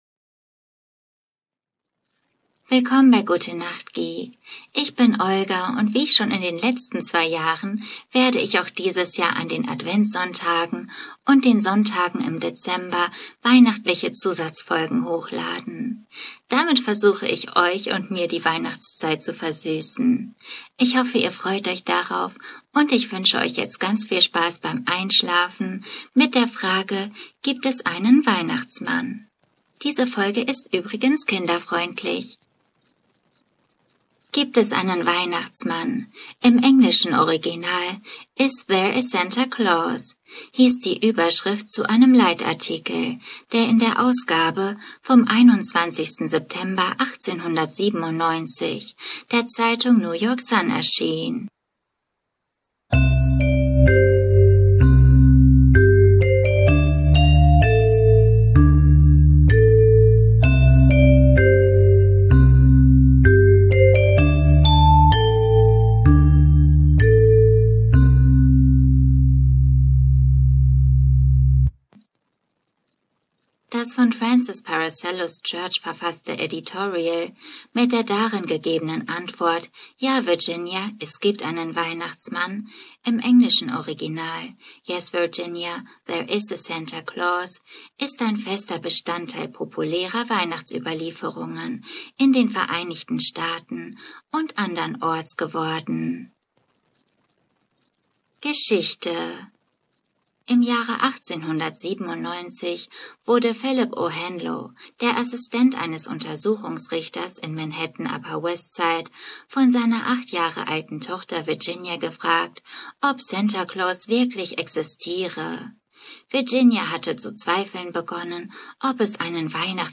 Heute wird die Frage, ob es den Weihnachtsmann gibt, ganz offiziell beantwortet. Als Kind habe ich mich dafür auf die Lauer gelegt, Wecker gestellt und heute habe ich gegoogelt und glücklicherweise war ich nicht die einzige mit der Frage, so gibt es einen berühmten Zeitungsartikel, der die Frage beantwortet und den passenden Wikipedia Artikel zum Vorlesen und Einschlafen.